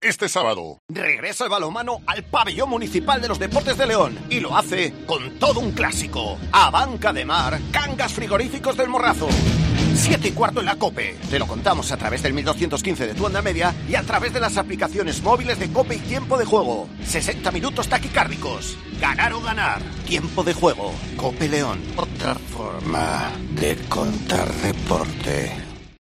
Escucha la cuña promocional del partido Ademar-Cangas del Morrazo el día 04-12-21 a las 19:30 h en el 1.215 OM